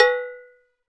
Index of /90_sSampleCDs/NorthStar - Global Instruments VOL-2/PRC_Cowbells/PRC_Cowbells